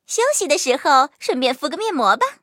M2中坦小破修理语音.OGG